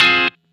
Track 08 - Guitar Stab OS 01.wav